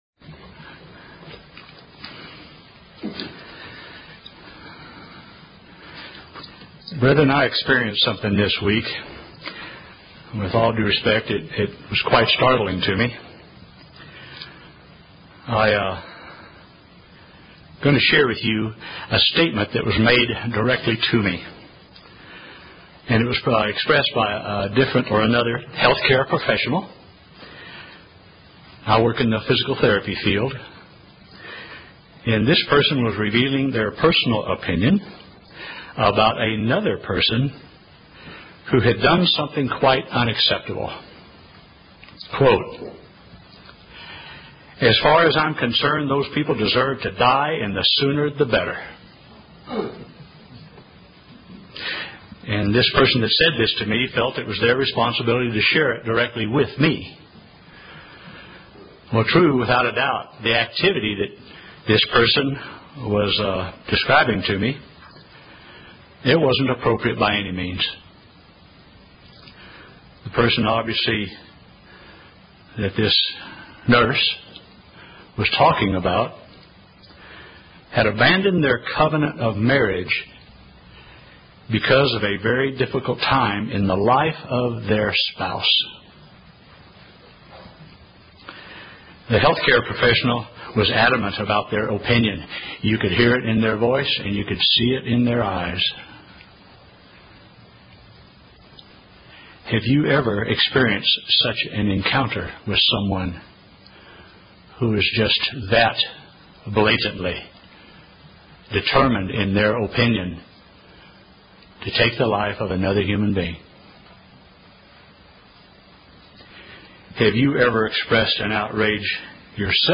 Sermons
Given in Oklahoma City, OK